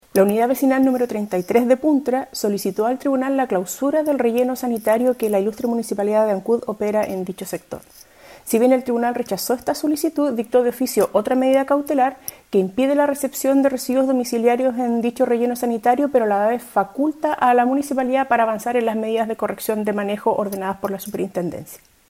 En ese contexto, la Ministra Sibel Villalobos, informó que el Tribunal Ambiental de Valdivia rechazó la clausura total del recinto solicitada por los reclamantes, pero paralizó el ingreso de residuos en las instalaciones.